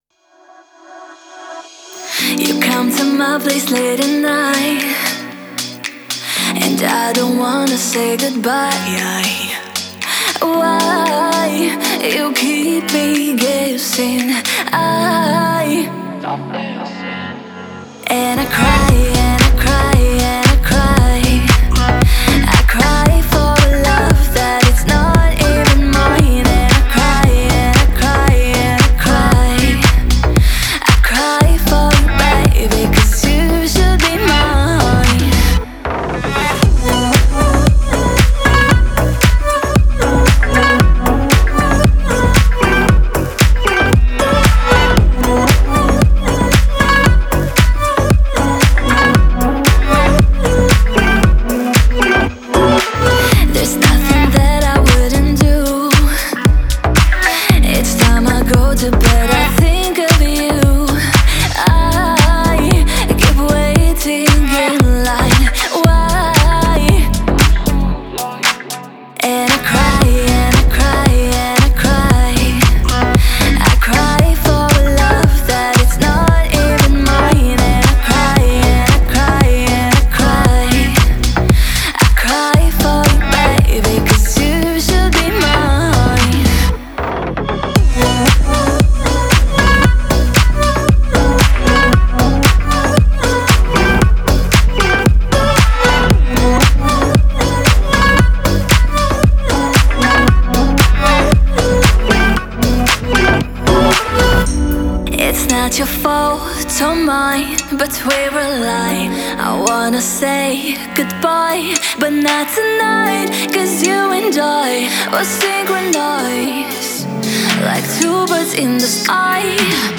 проникновенная поп-баллада